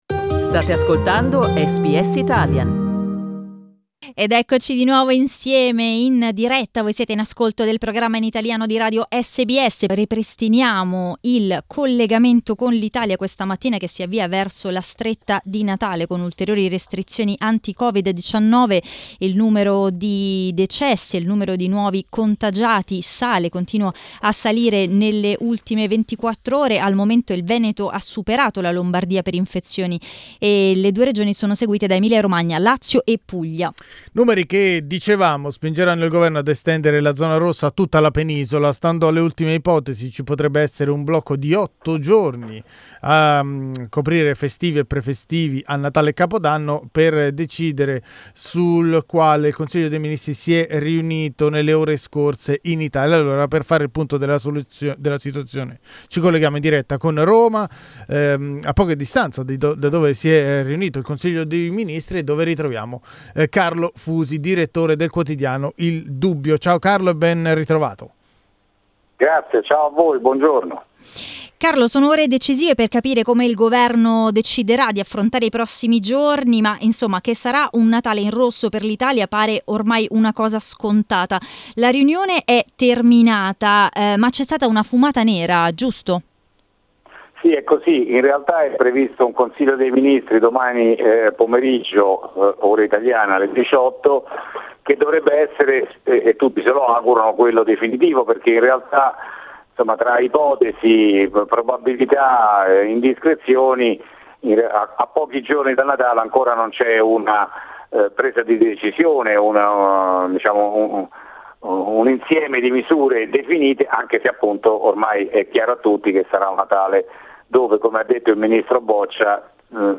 in collegamento dall'Italia